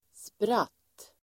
Uttal: [sprat:]